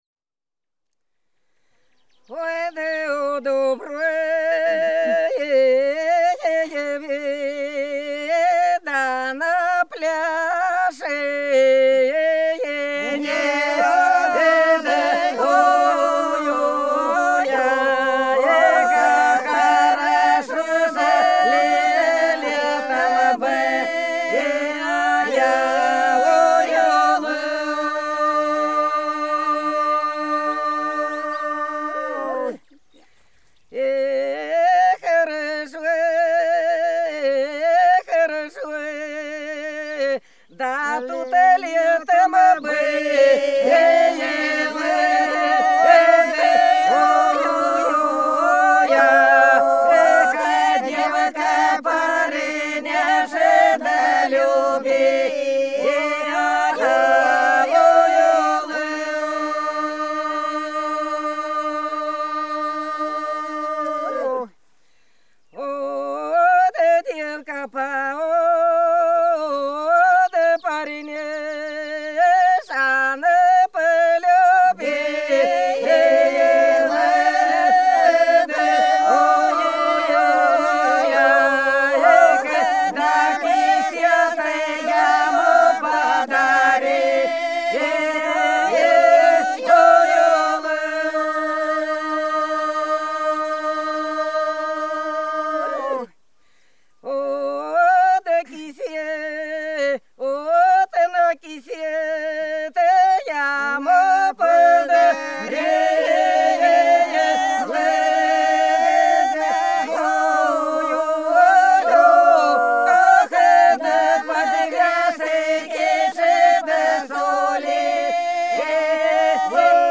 Белгородские поля (Поют народные исполнители села Прудки Красногвардейского района Белгородской области) У дуброве на плешине - протяжная, весновая